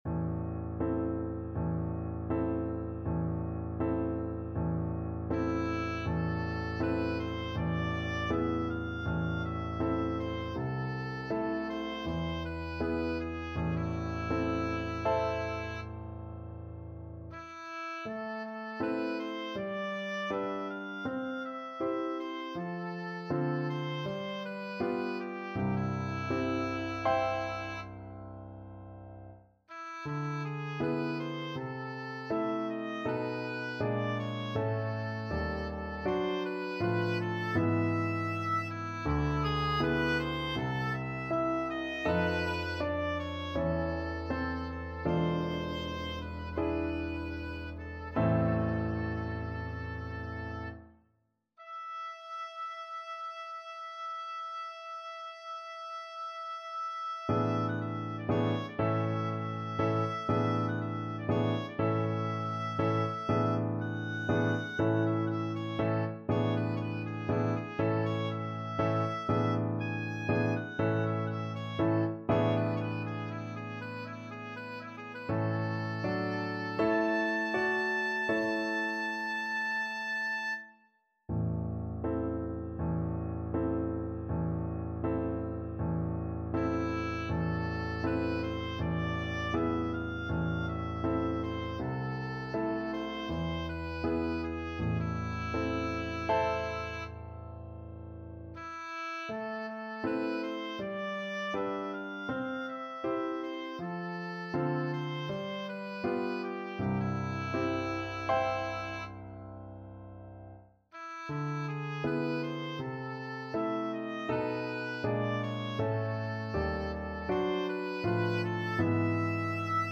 Oboe
4/4 (View more 4/4 Music)
Un poco andante
A minor (Sounding Pitch) (View more A minor Music for Oboe )
Classical (View more Classical Oboe Music)